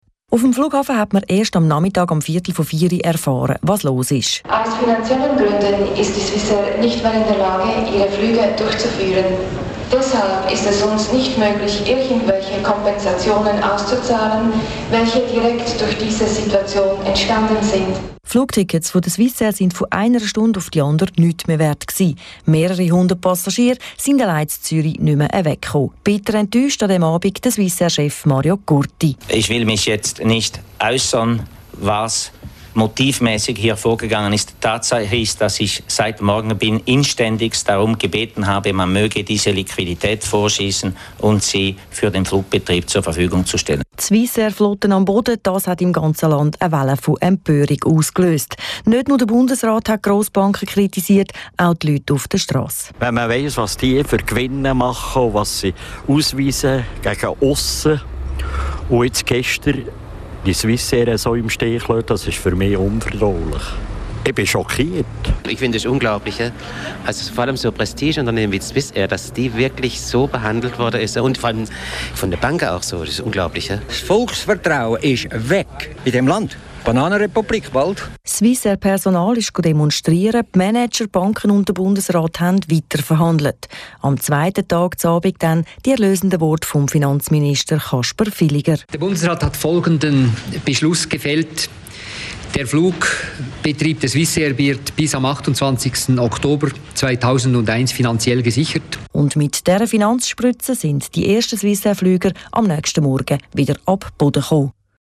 Die Nation war schockiert und lernte ein neues Wort: Grounding. Töne von damals.